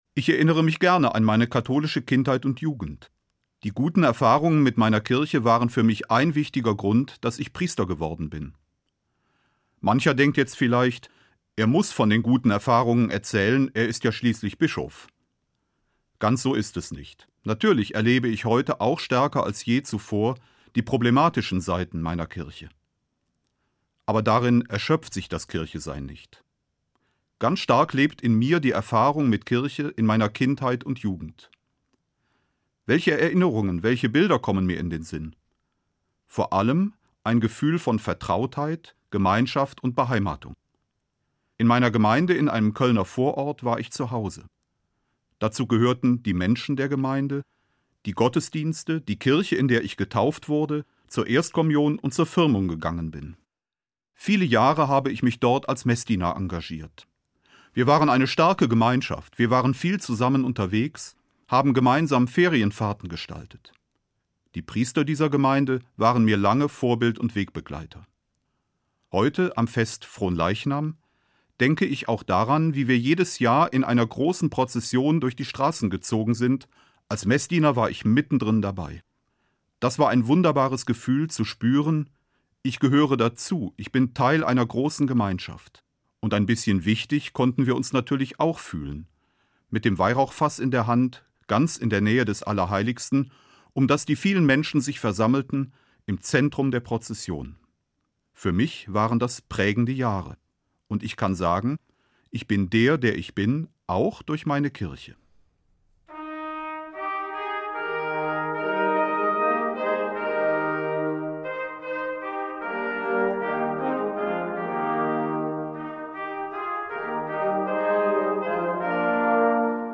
Eine Sendung von Peter Kohlgraf, Bischof von Mainz